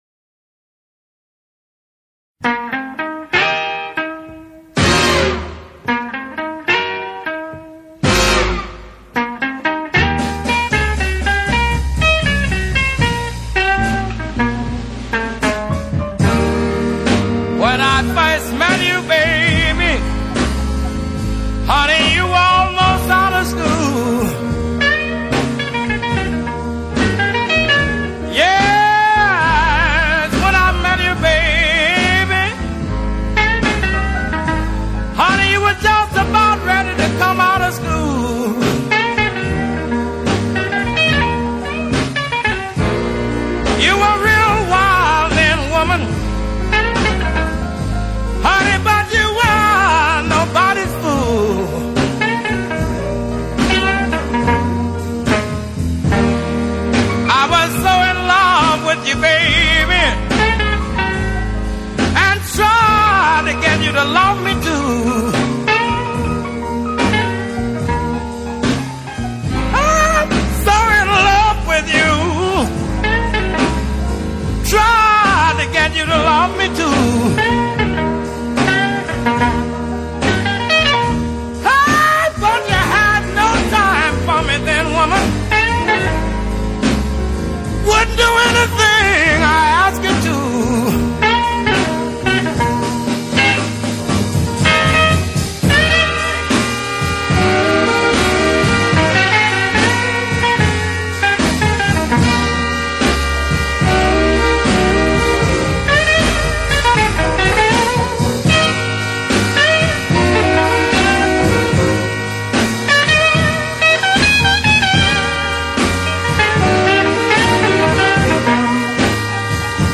آلبوم کلاسیک بلوز
Electric Blues, Rhythm & Blues, Soul Blues